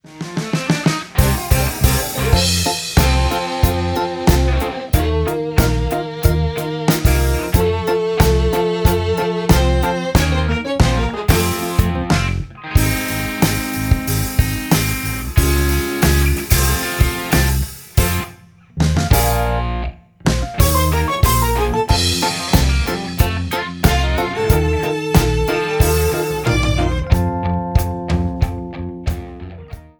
Musical Backing Tracks